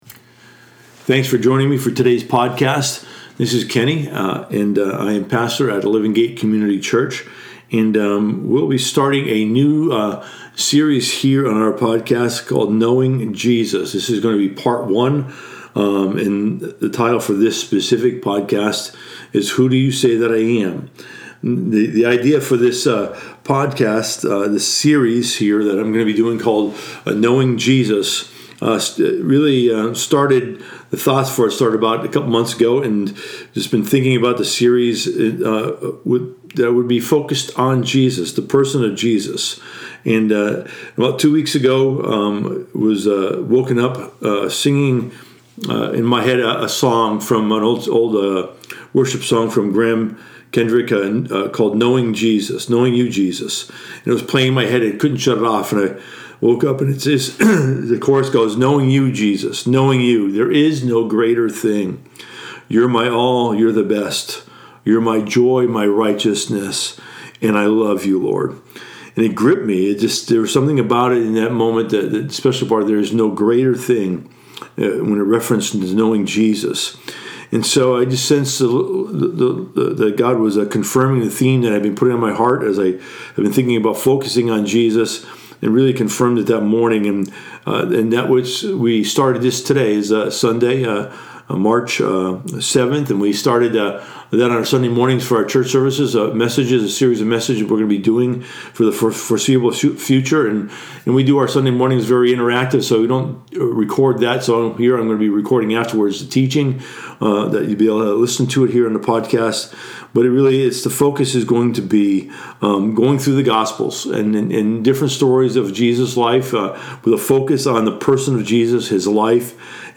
This is the first teaching in a series titled, “Knowing Jesus”. This teaching is titled “Who Do You Say That I Am” . It examines the process of growing in knowing the person Jesus; knowing His life, His heart, His teachings and His ways.